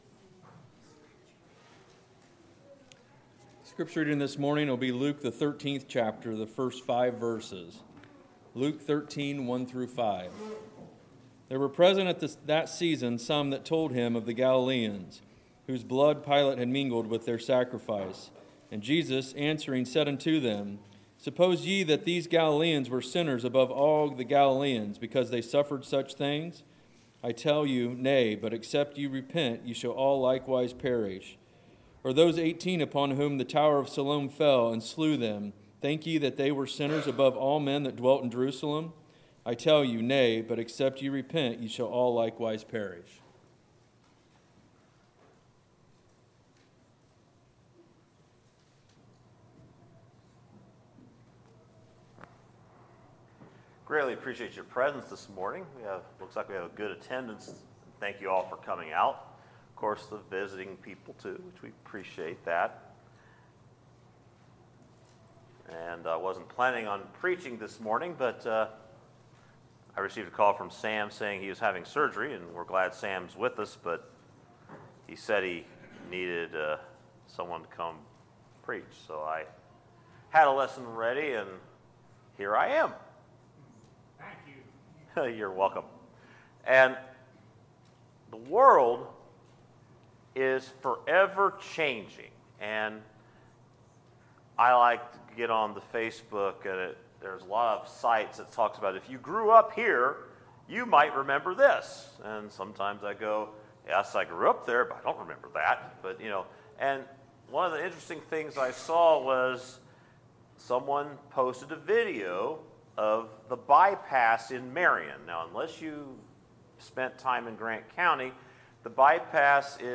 Sermons, September 22, 2019